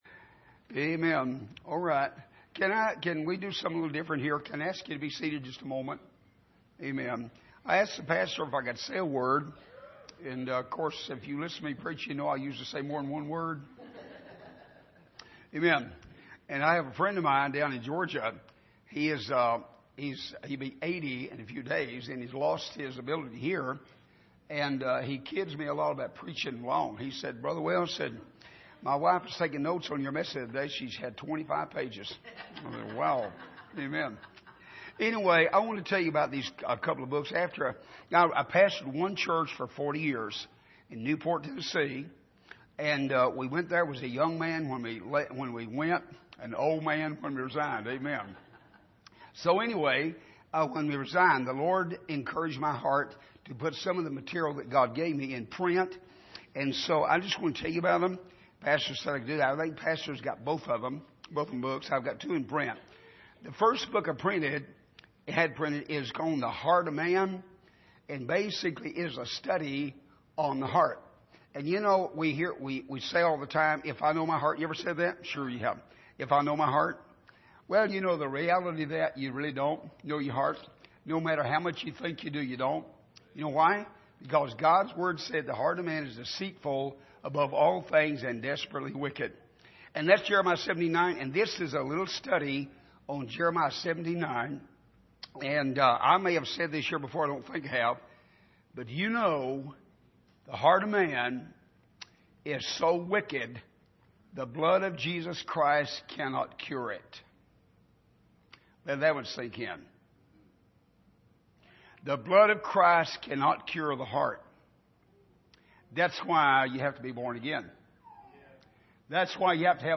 Miscellaneous Passage: Numbers 31:1-2 Service: Sunday Evening The Last Opportunity To Serve « It Is Good For Us To Be Here The Sword of Justice Awakened Against His Fellow